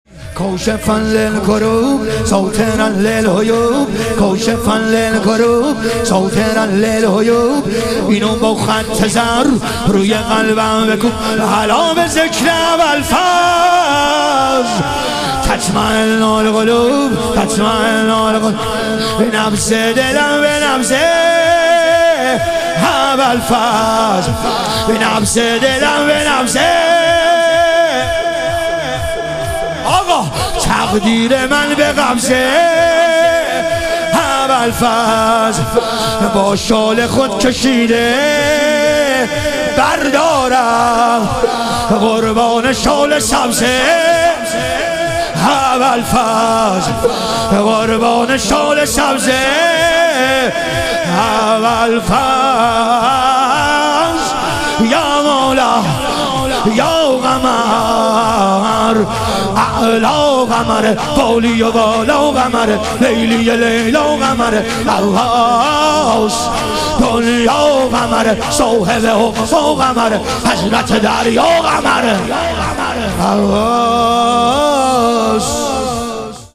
شهادت حضرت جعفرطیار علیه السلام - شور